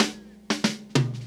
Classic Fill.wav